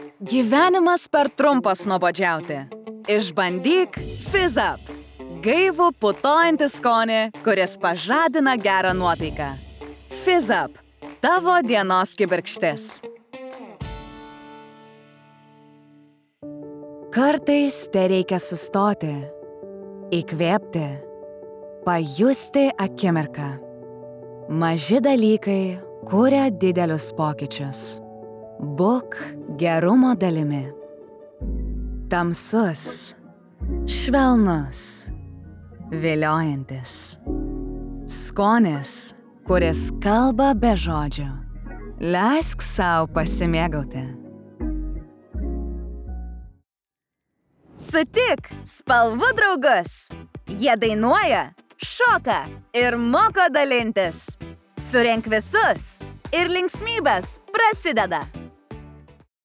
Konversation
Zuversichtlich
Warm